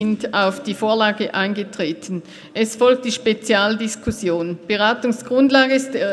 Session des Kantonsrates vom 26. bis 28. November 2018
Ratspräsidentin, stellt Eintreten auf die Vorlage fest.